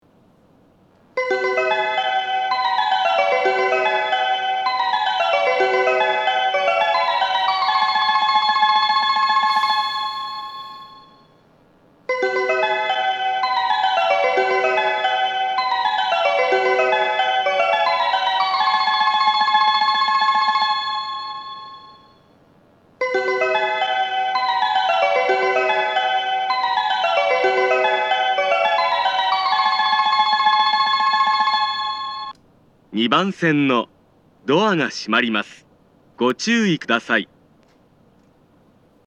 発車メロディー
2.9コーラスです!交換がなければ余韻まで鳴りやすいです。